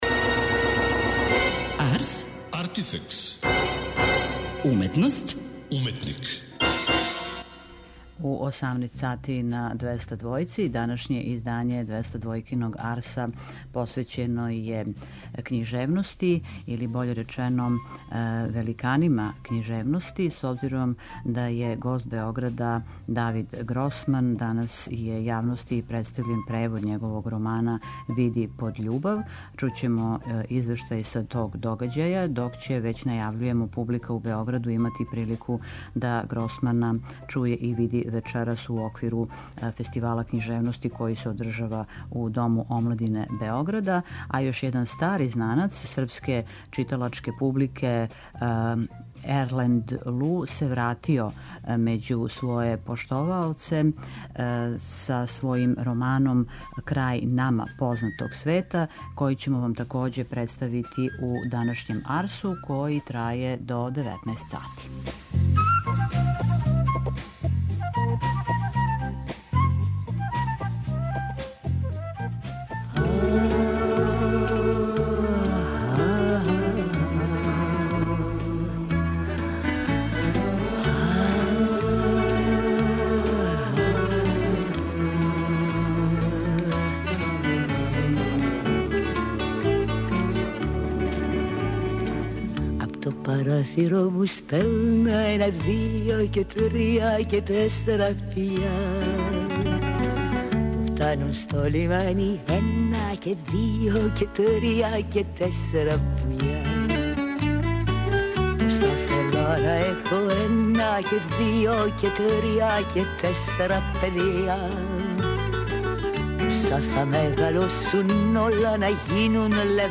У данашњој емисији чућете разговор са овим књижевником.